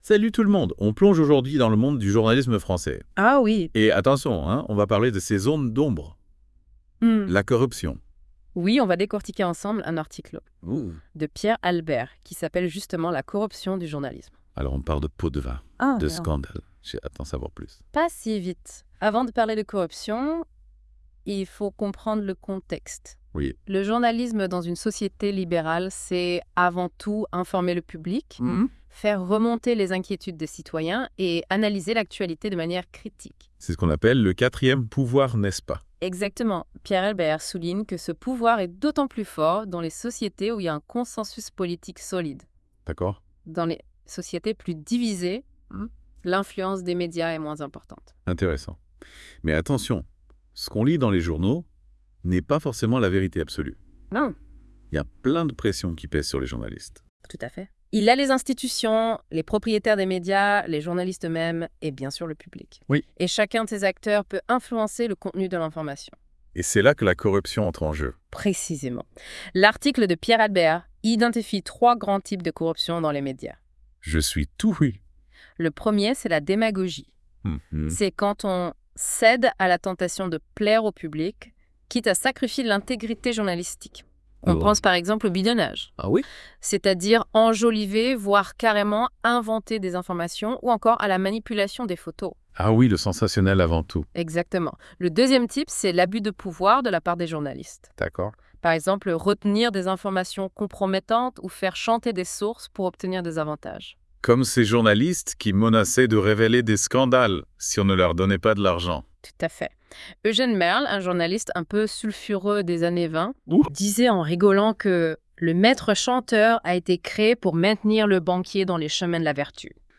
Débat en Podcast : La corruption dans le journalisme